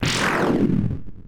snd_explosion_mmx3.wav